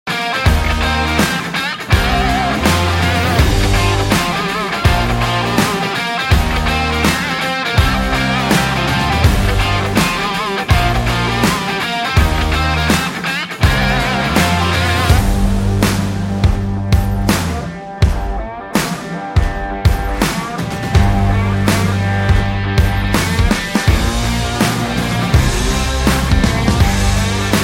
R&B
Smooth. Soulful. Magnetic.